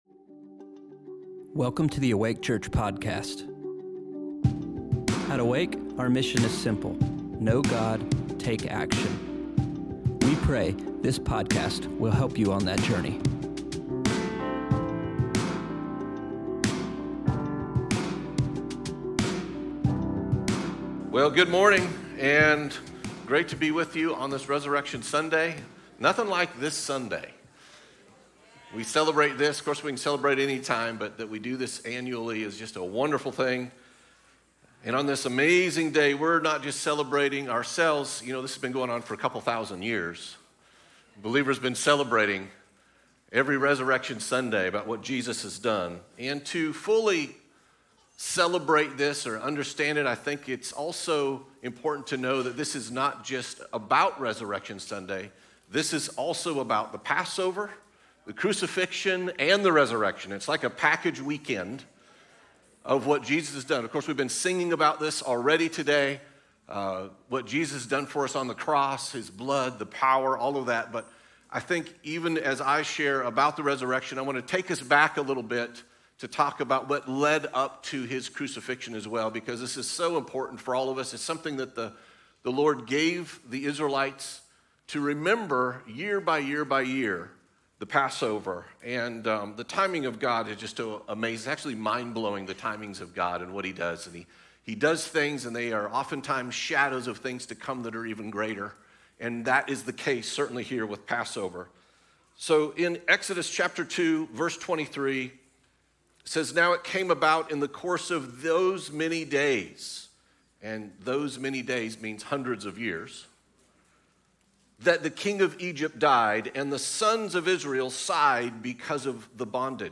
Weekly messages from Awake Church in Winston Salem, NC.